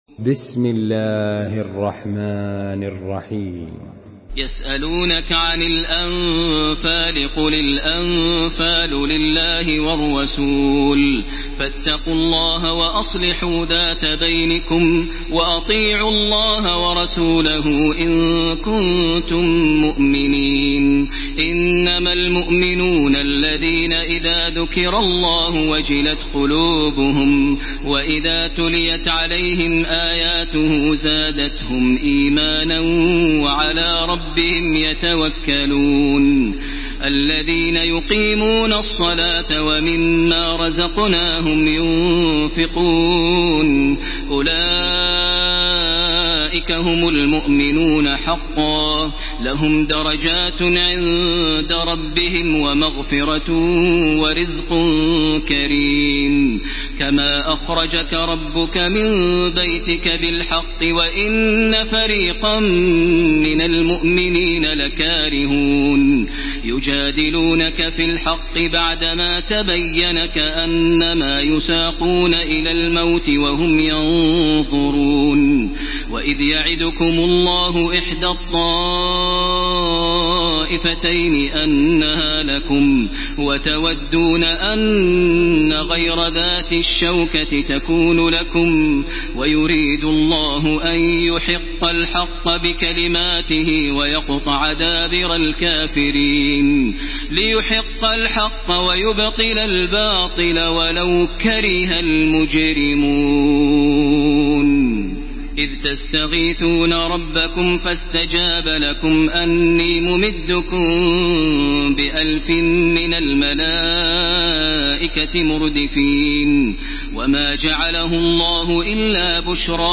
Surah Al-Anfal MP3 in the Voice of Makkah Taraweeh 1434 in Hafs Narration
Murattal